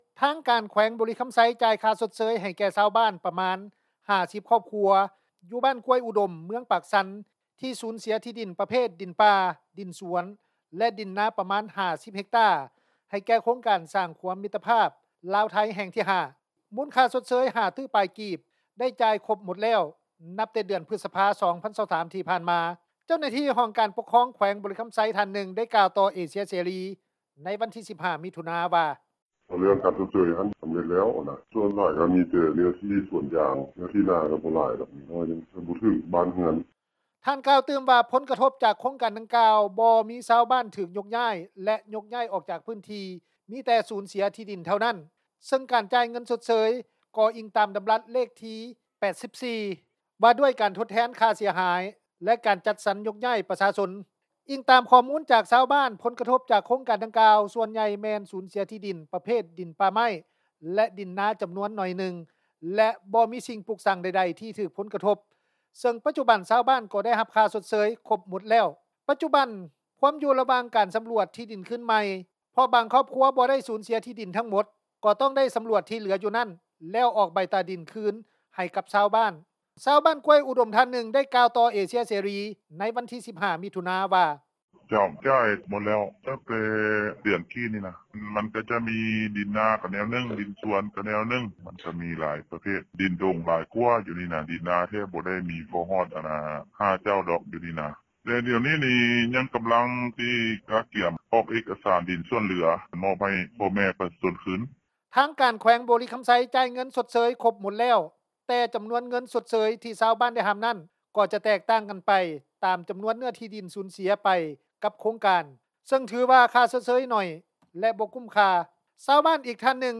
ຊາວບ້ານກ້ວຍອຸດົມ ທ່ານນຶ່ງ ກ່າວຕໍ່ວິທຍຸ ເອເຊັຽ ເສຣີ ໃນວັນທີ 15 ມິຖຸນາ ວ່າ:
ຊາວບ້ານອີກທ່ານນຶ່ງ ໄດ້ກ່າວຕໍ່ວິທຍຸ ເອເຊັຽເສຣີ ວ່າ: